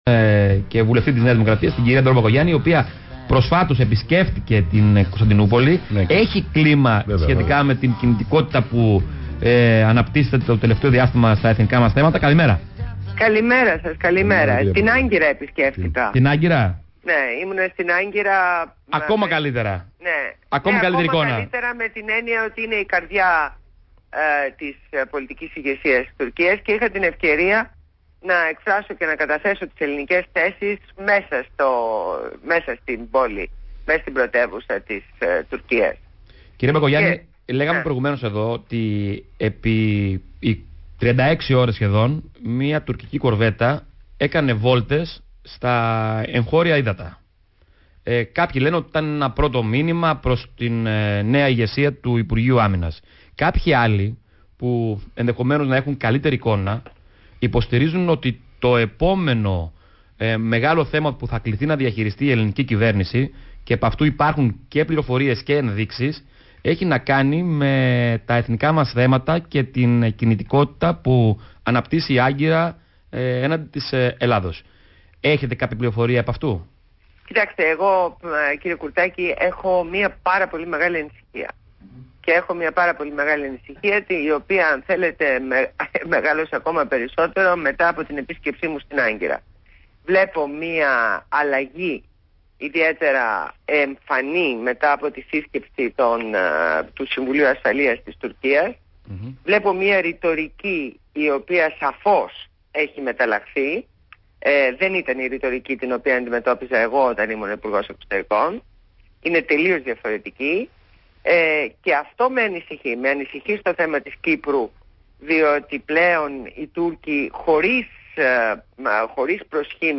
Συνέντευξη στο ραδιόφωνο Παραπολιτικά 90,1fm με τους δημοσιογράφους